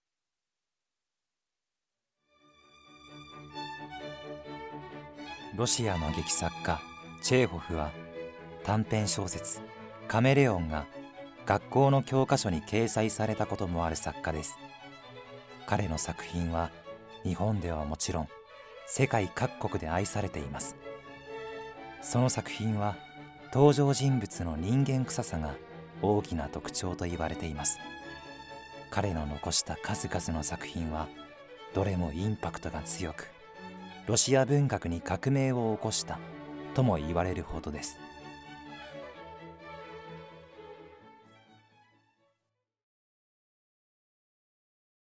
ナレーション@